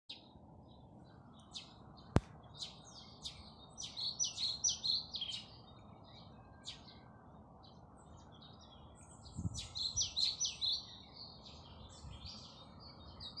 Pied Flycatcher, Ficedula hypoleuca
StatusSinging male in breeding season
NotesDzied piemājas kokos (ozoli, kļavas)